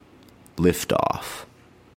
描述：与科幻相关的口头文本样本。
标签： 语音 英语 科幻 美国航空航天局 电火花 声乐 口语 空间
声道立体声